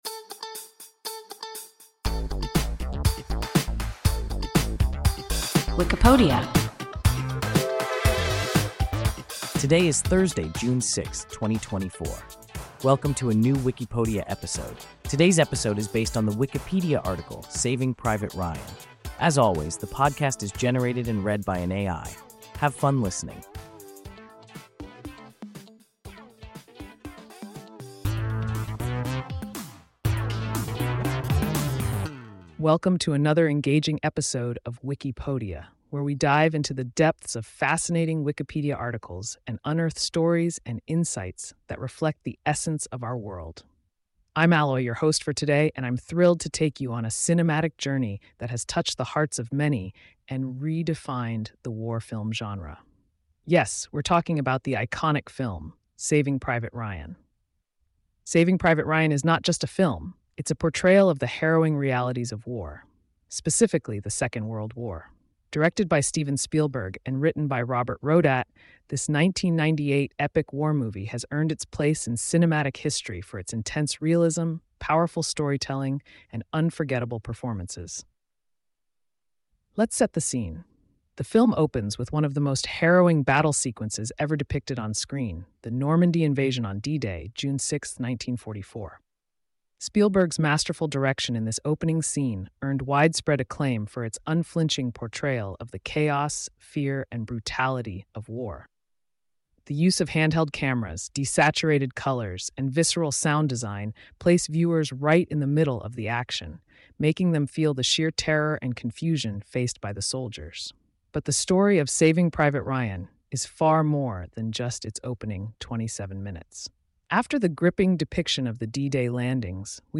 Saving Private Ryan – WIKIPODIA – ein KI Podcast